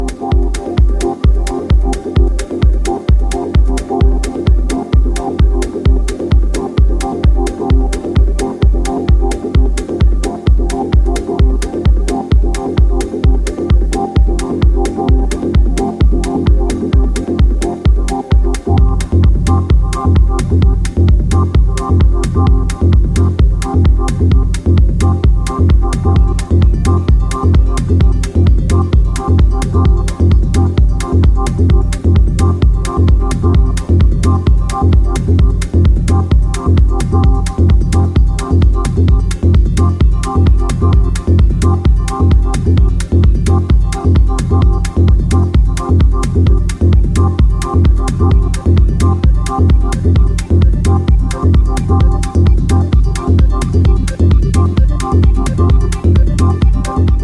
Norwegian techno classic